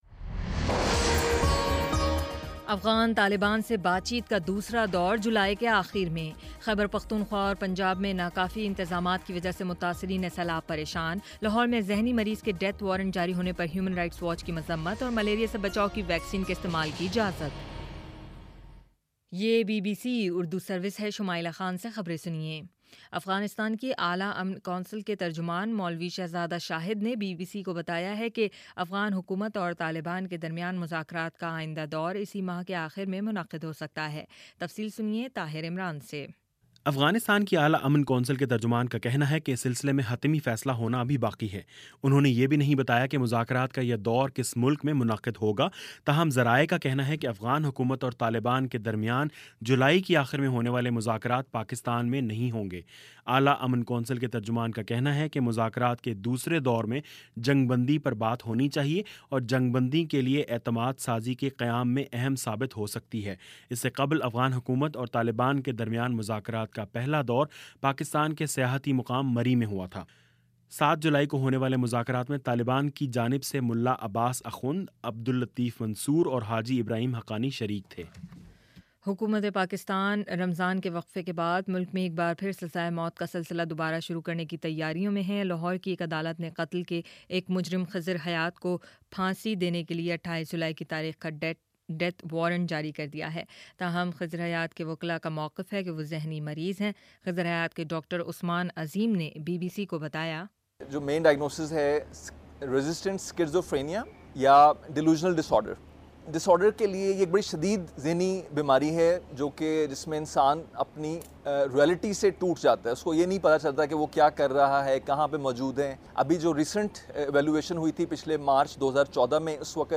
جولائی 24: شام سات بجے کا نیوز بُلیٹن